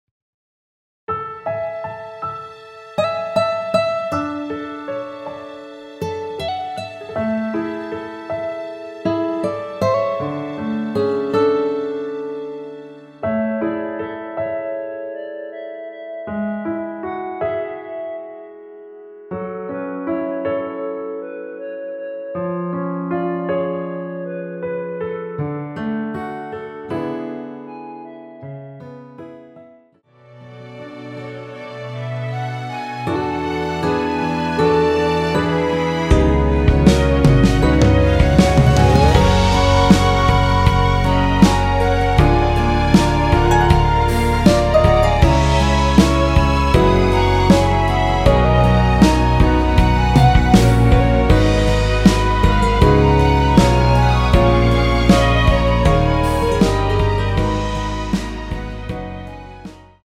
원키에서(+5)올린 (1절앞+후렴)으로 진행되는 멜로디 포함된 MR입니다.
앞부분30초, 뒷부분30초씩 편집해서 올려 드리고 있습니다.
중간에 음이 끈어지고 다시 나오는 이유는